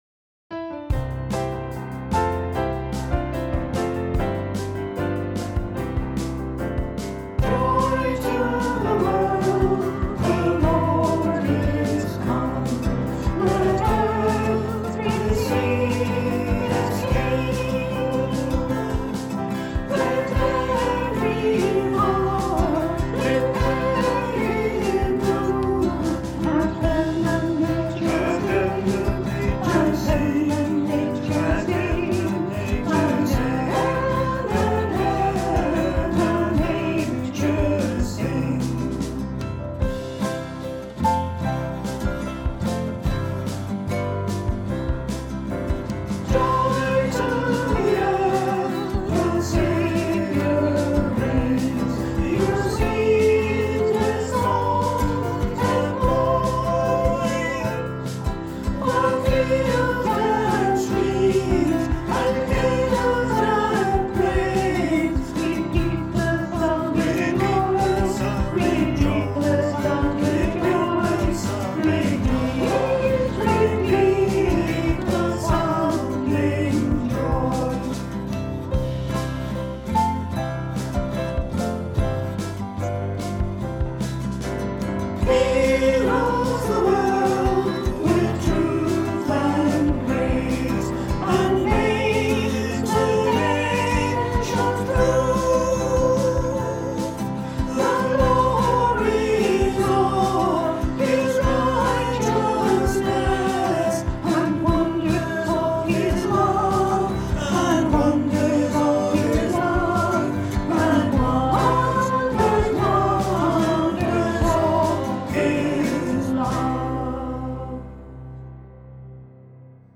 Sermon – Sunday 27 December 2020